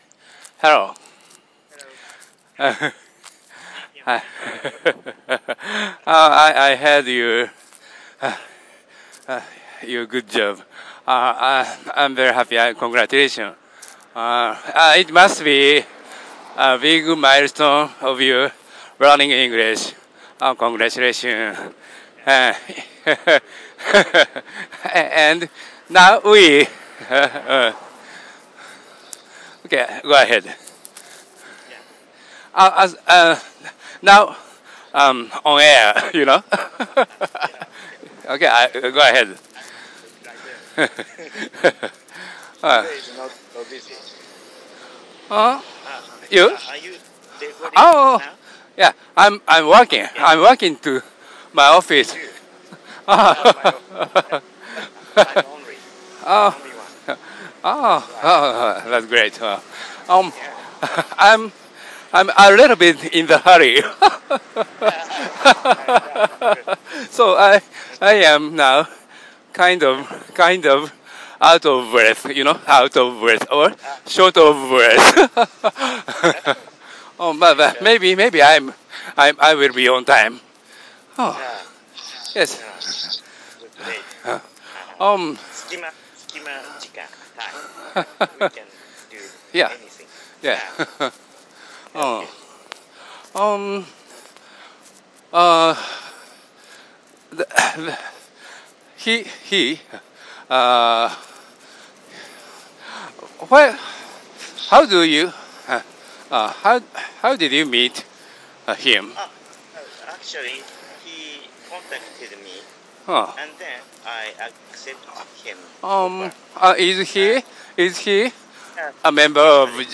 A Conversation in English by Two Japanese Men